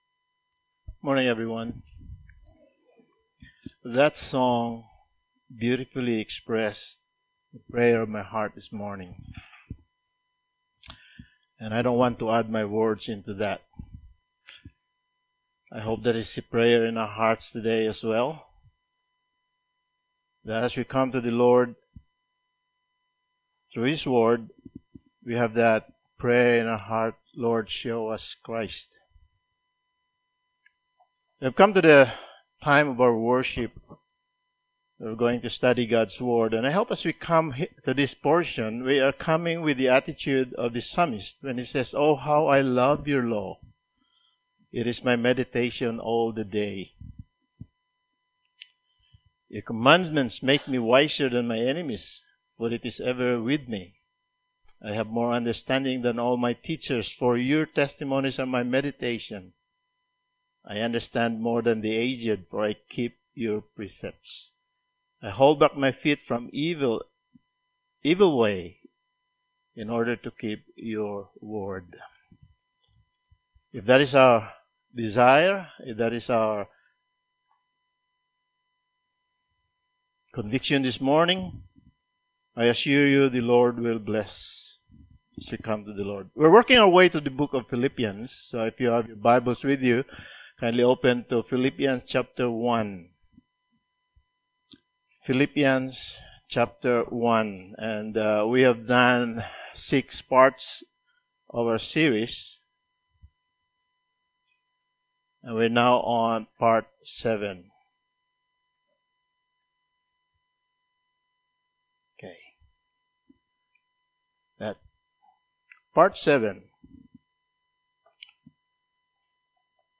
Passage: Philippians 1:27-30 Service Type: Sunday Morning